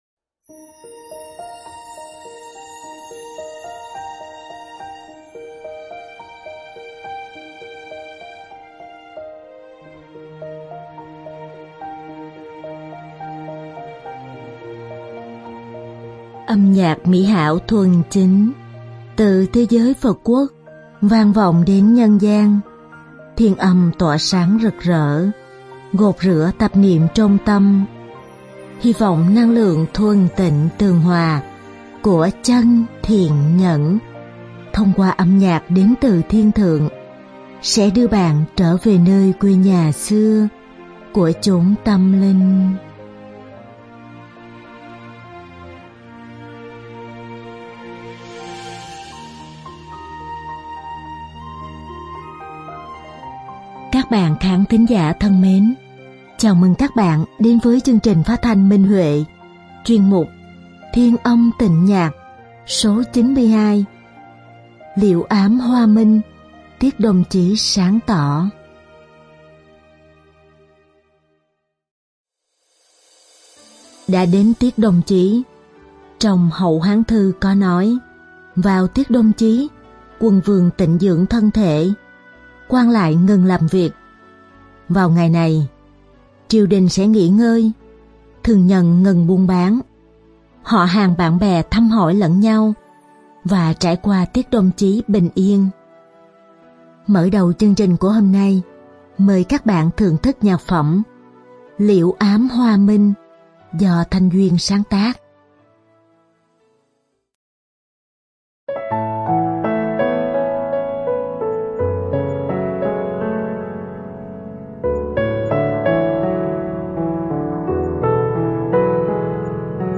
Đơn ca nữ
Bài hát thiếu nhi